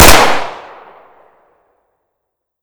Tommy Gun Drop / gamedata / sounds / weapons / thompson